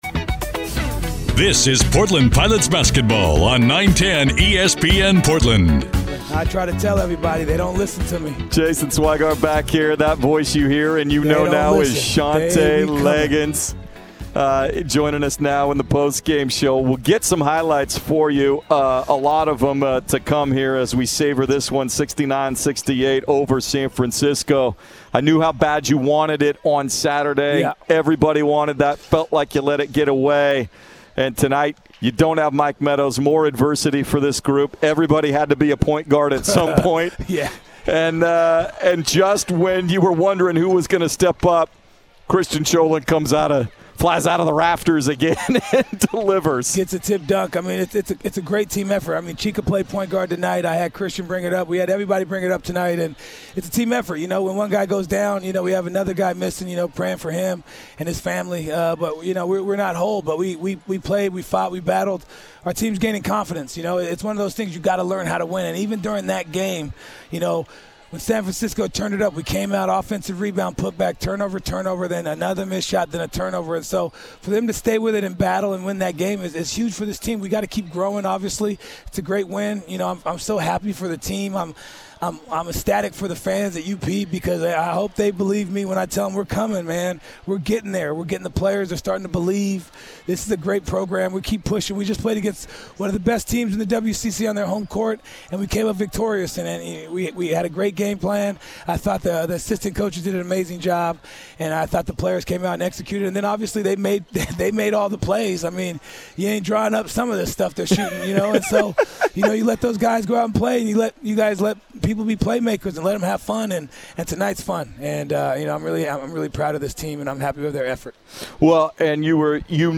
Postgame Radio and Highlights at San Francisco
Interview and highlights courtesy of 910 ESPN Portland (KMTT).